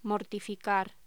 Locución: Mortificar
voz